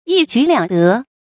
注音：ㄧ ㄐㄨˇ ㄌㄧㄤˇ ㄉㄜ
一舉兩得的讀法